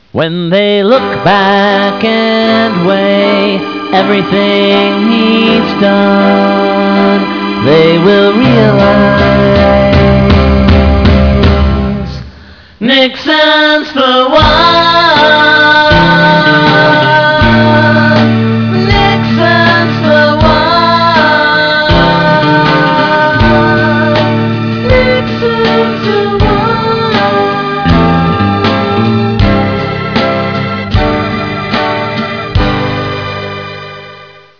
(8-bit / Mono / 34 sec / 372K)